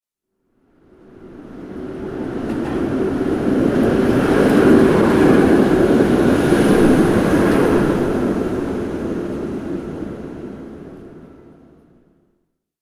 جلوه های صوتی
دانلود آهنگ قطار 15 از افکت صوتی حمل و نقل
دانلود صدای قطار 15 از ساعد نیوز با لینک مستقیم و کیفیت بالا